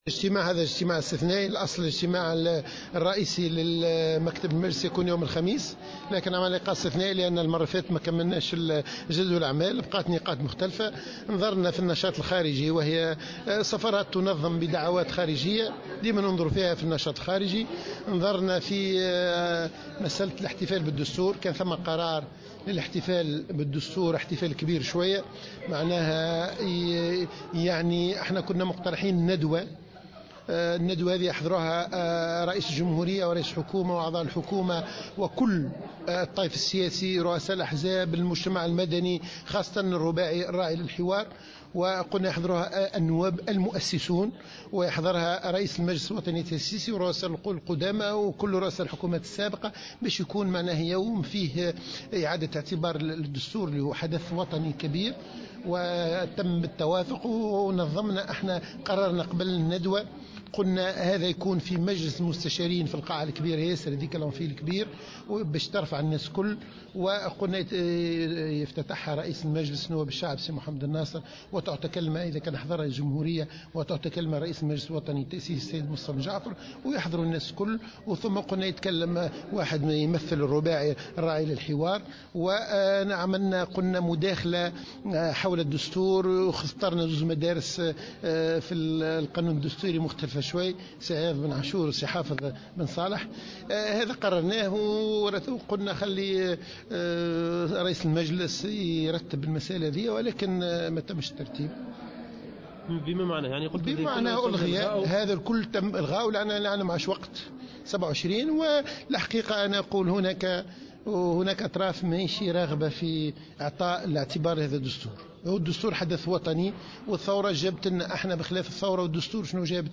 وقال النائب عن حركة النهضة صحبي عتيق في تصريح لمراسلة الجوهرة اف ام بالبرلمان، إن هناك أطراف سياسيّة ليس لها إرادة في إعطاء قيمة اعتبارية لهذا الحدث الوطني والاحتفال بالدستور.